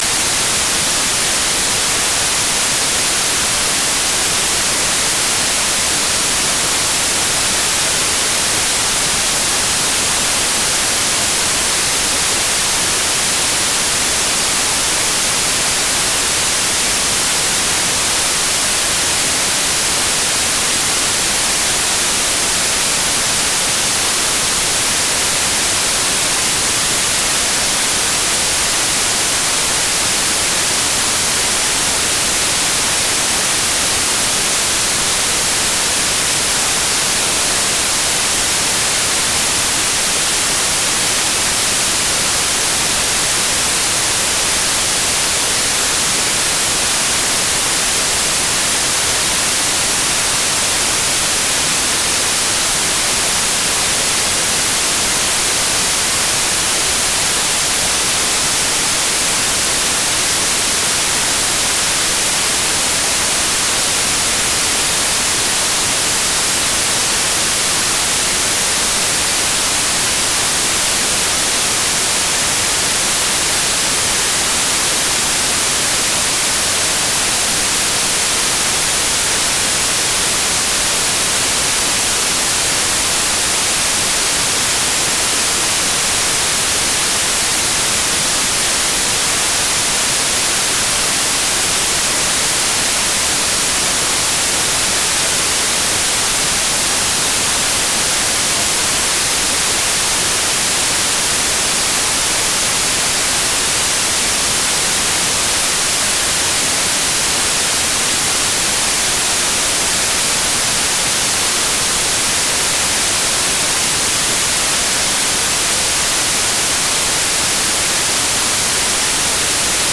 خوابیدن بچه white voice
download-white-voice(1).mp3